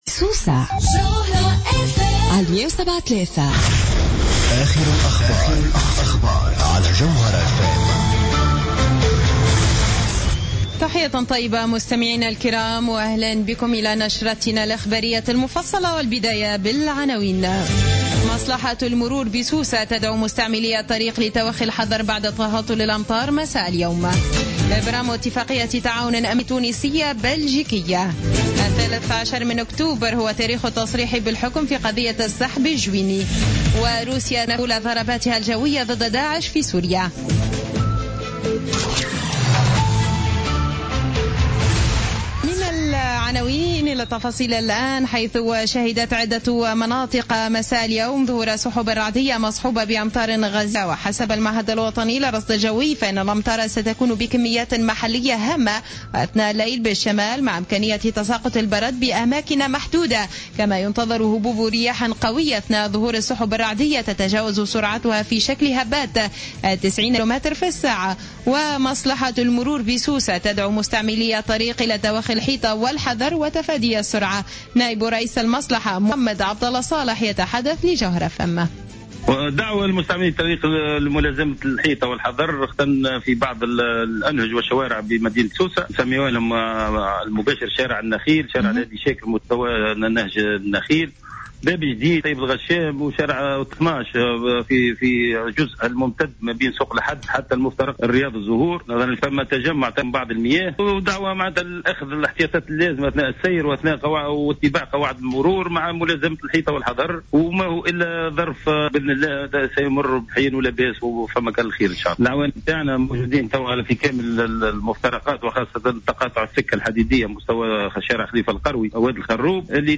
نشرة أخبار السابعة مساء ليوم الأربعاء 30 سبتمبر 2015